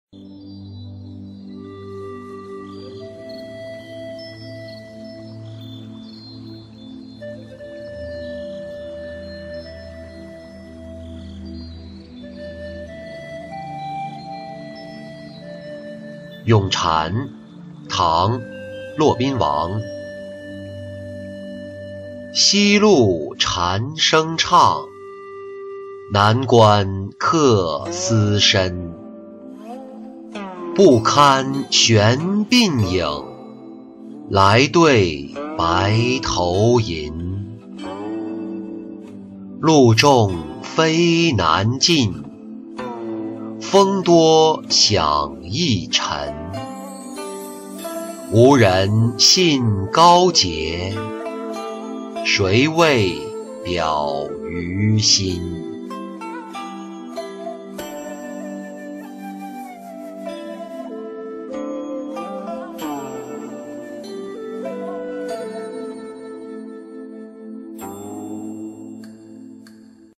在狱咏蝉-音频朗读